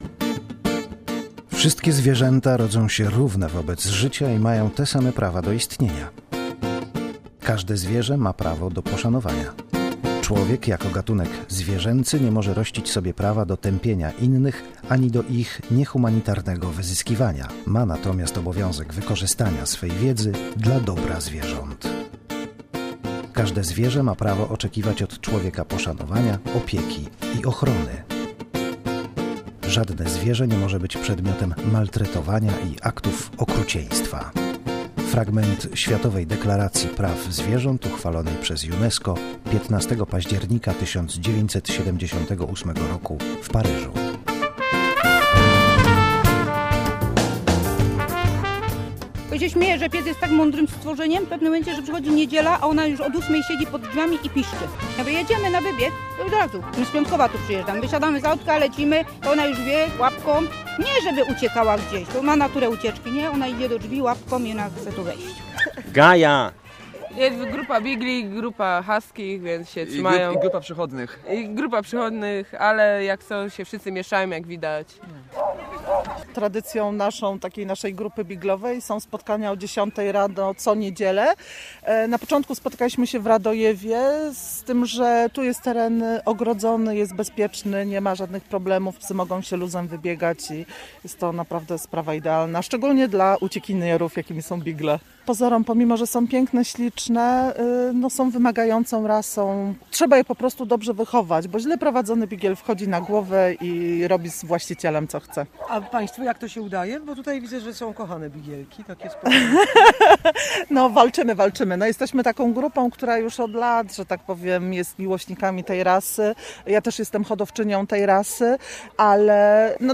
Pieskie życie - reportaż